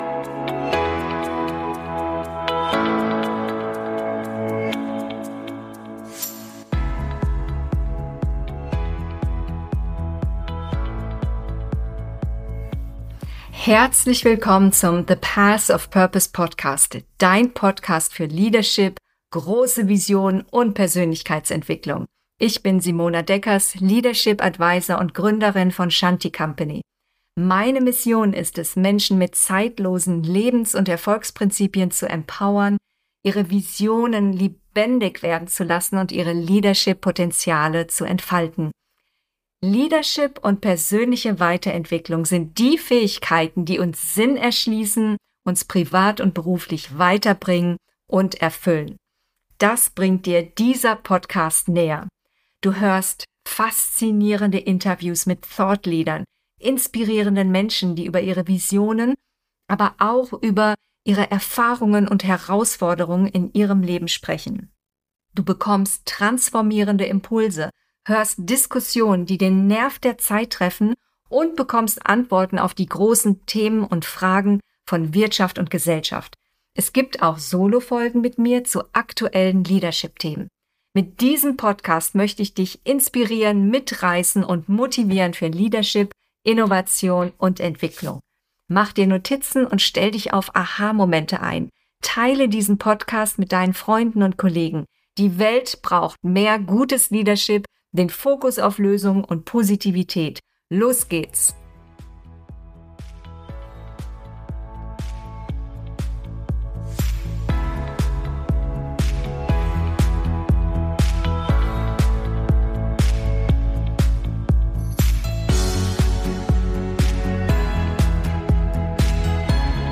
Strategievorteil und Erfolgsfaktor: Altersvielfalt - Interview